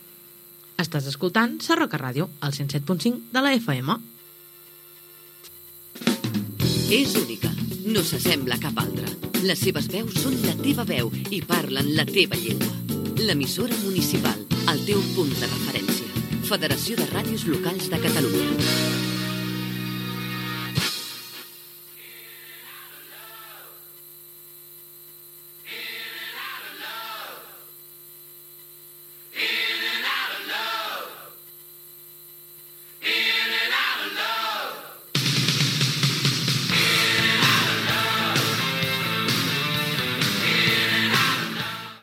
5bbc232c1149ed0bb9b4afe3b8ec12a4a7b93b4e.mp3 Títol Sarroca Ràdio Emissora Sarroca Ràdio Titularitat Pública municipal Descripció Indicatiu de l'emissora i de la Federació de Ràdios Locals de Catalunya, tema musical.